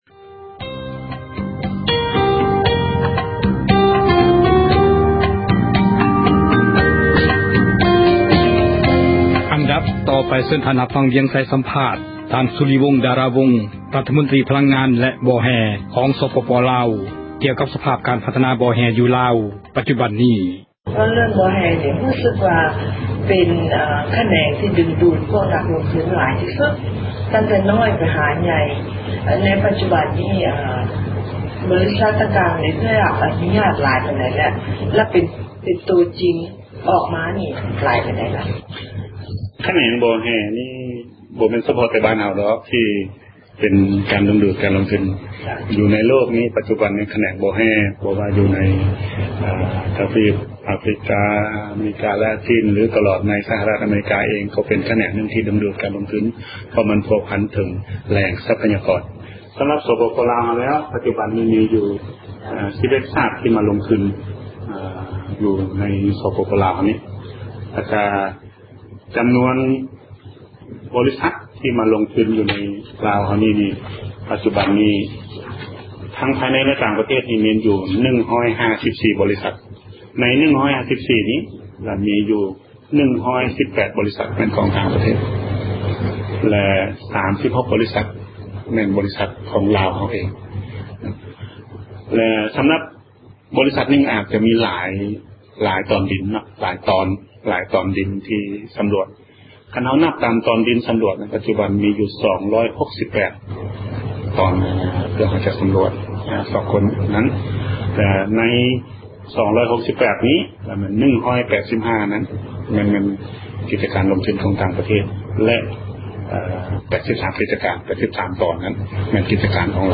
F-Soulivong ທ່ານ ສຸລິວົງ ດາຣາວົງ ຣັຖມົນຕຣີ ພລັງງານແລະບໍ່ແຮ່ ຂອງສປປລາວ ກໍາລັງໃຫ້ສັມພາດ ແກ່ຜູ້ສື່ຂ່າວ ເອເຊັຍເສຣີ ໃນວັນທີ 21 ມິຖຸນາ 2010